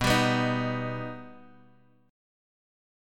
B+ chord